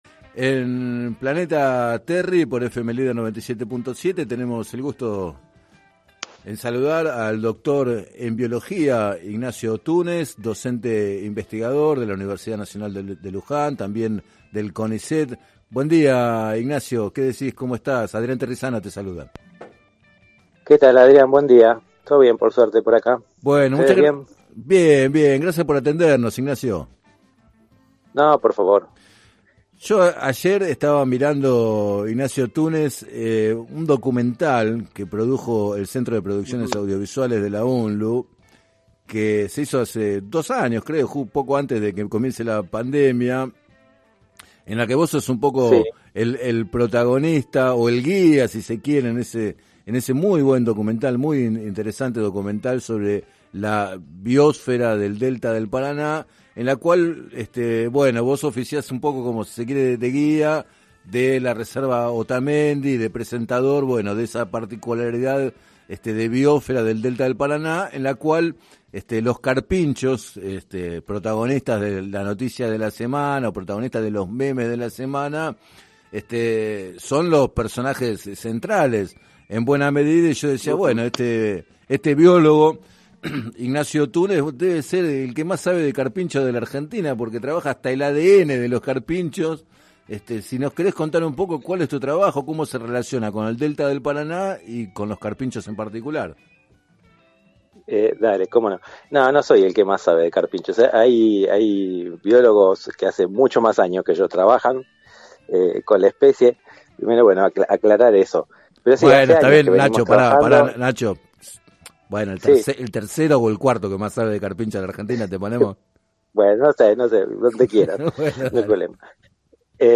Entrevistado en el programa “Planeta Terri” de FM Líder 97.7